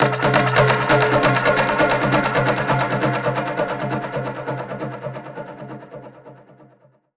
Index of /musicradar/dub-percussion-samples/134bpm
DPFX_PercHit_E_134-05.wav